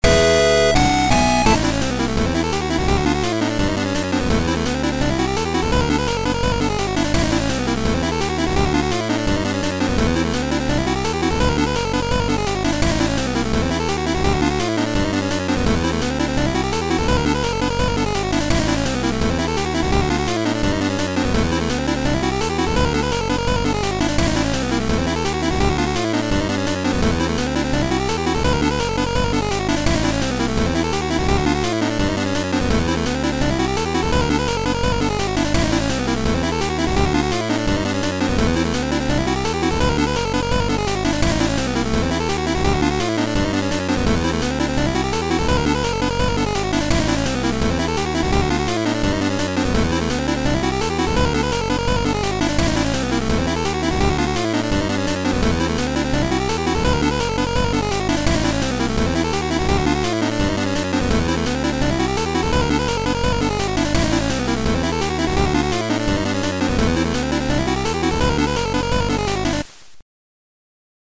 game ost 8-bit